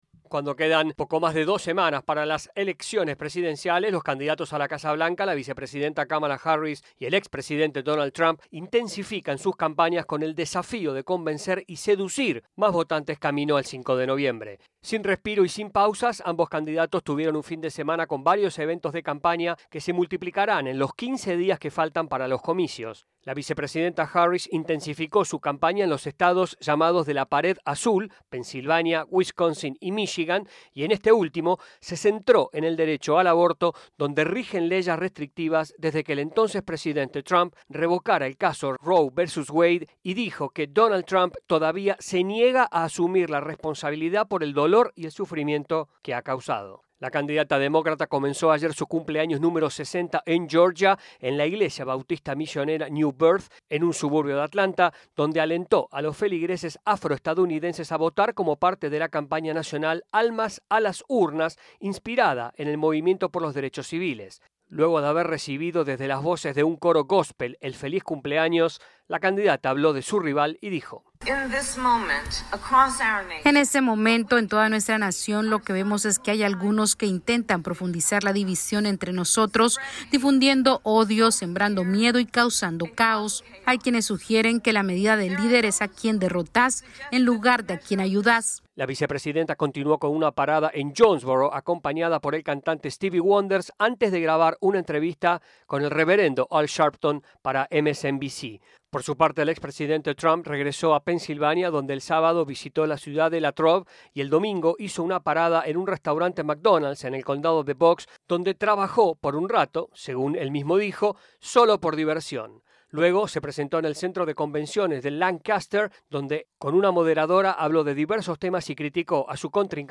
AudioNoticias
desde la Voz de América en Washington DC